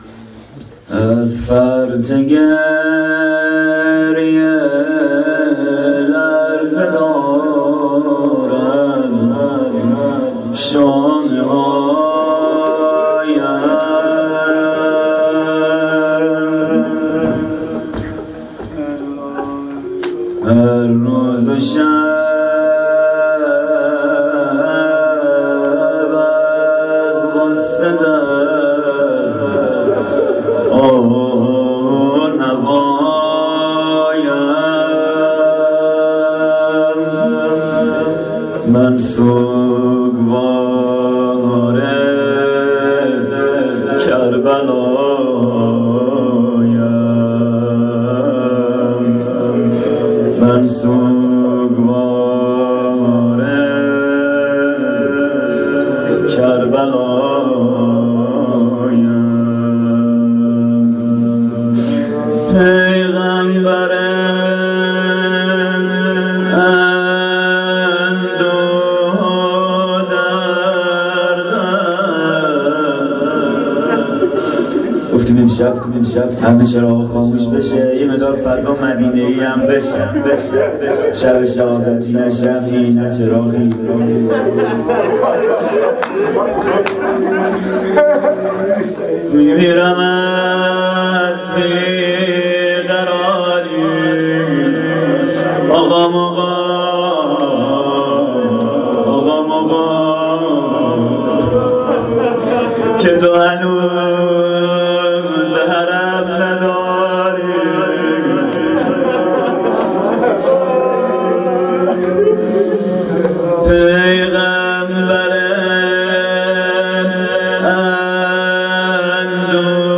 roze-shab1-moharam93.mp3